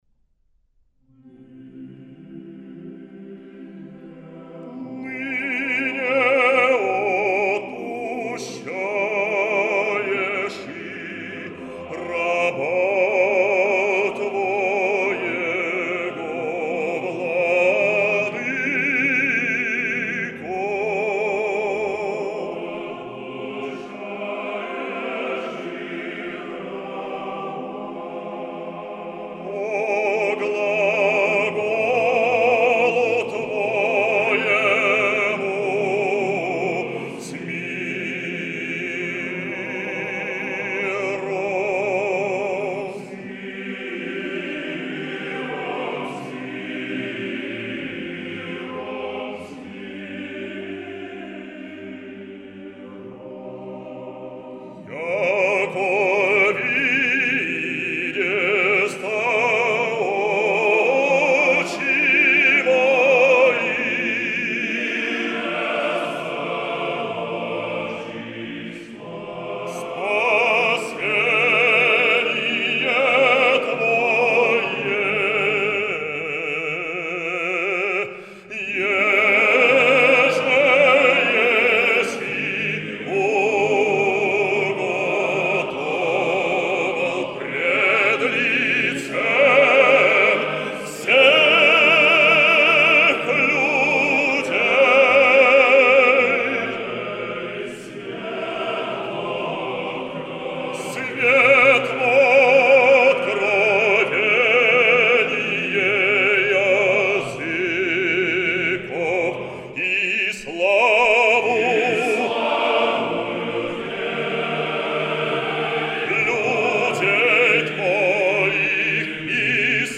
Оперное исполнение.
Благоговения нету и трепета духовного. Высоко поднято.